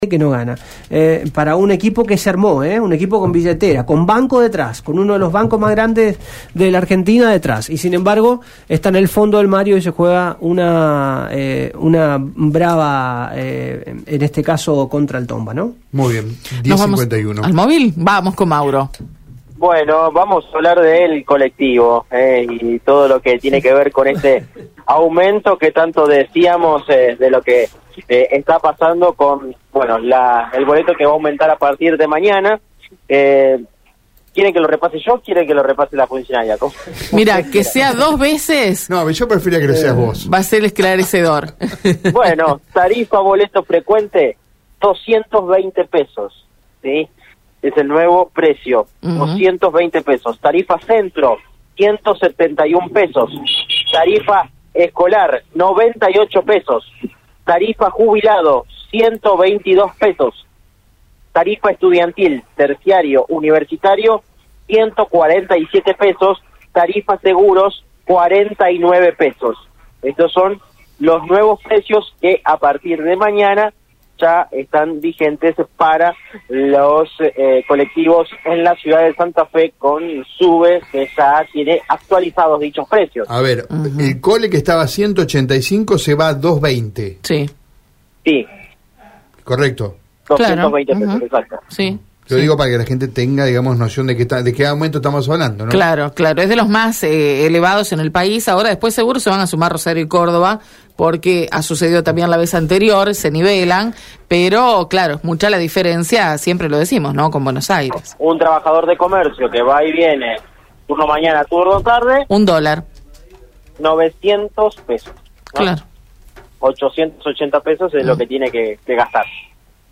En diálogo con el móvil de Radio EME, Andrea Zorzón, directora de Movilidad de Santa Fe, señaló: «El aumento se da en el marco de una inflación que no nos da tregua, un combustible que sigue aumentando, y una política de subsidios que no se ha modificado, y sigue siendo inequitativa».
Escuchá la palabra de Andrea Zorzón: